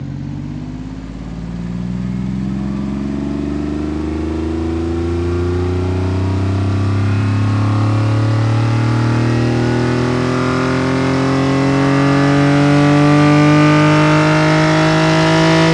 rr3-assets/files/.depot/audio/Vehicles/v6_02/v6_02_Accel.wav
v6_02_Accel.wav